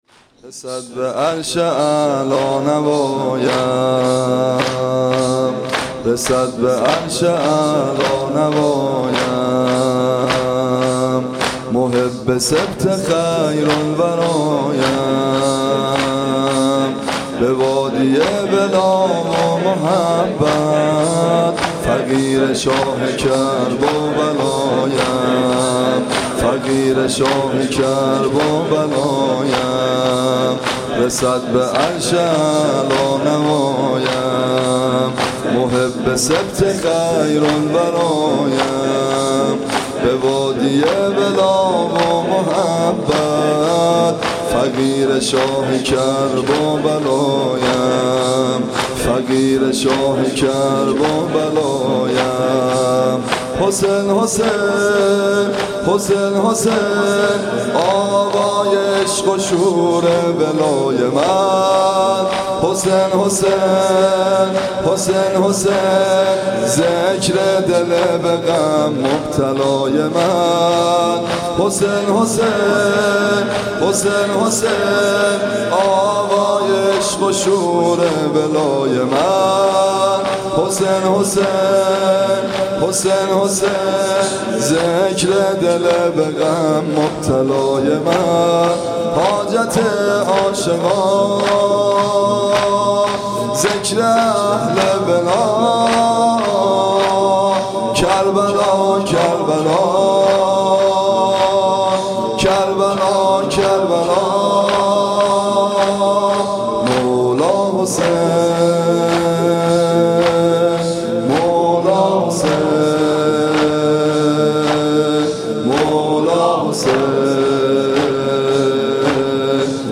صوت مراسم شب دوم محرم ۱۴۳۷ هیئت ابن الرضا(ع) ذیلاً می‌آید: